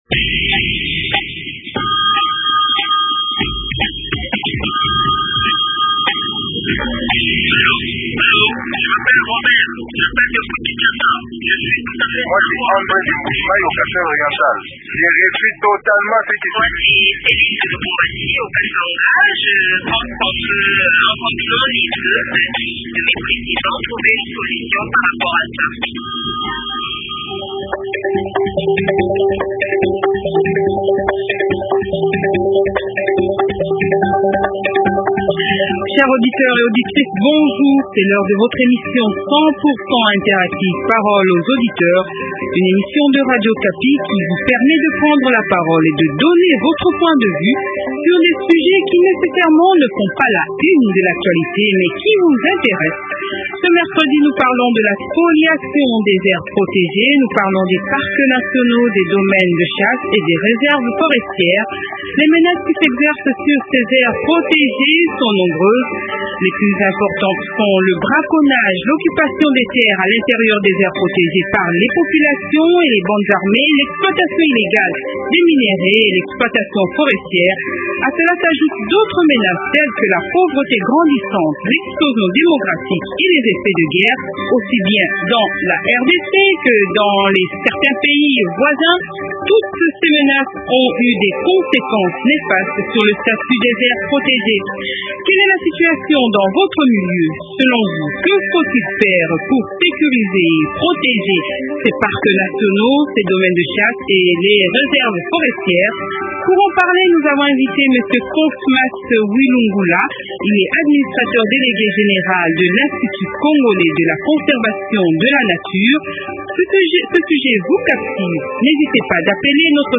Invité: Cosmas Wilungula ; Administrateur délégué général de l’ICCN (Institut congolais de la Conservation de la nature).